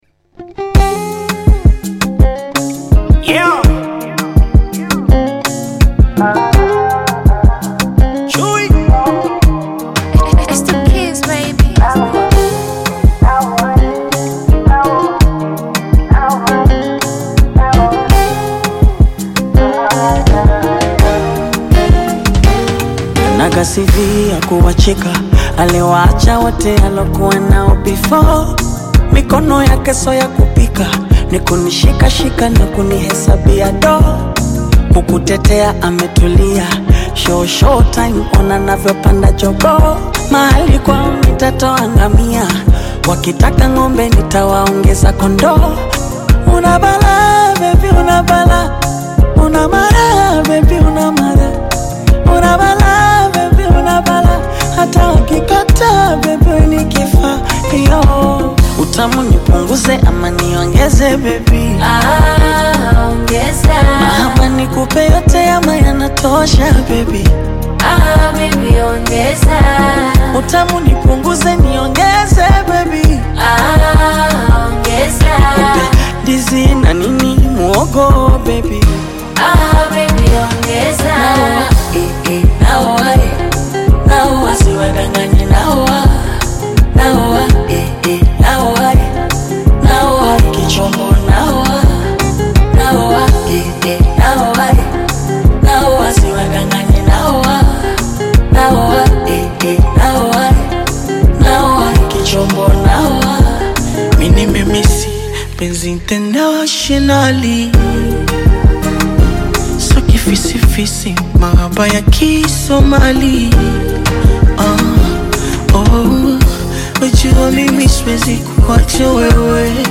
romantic Afro-Pop/Bongo Flava single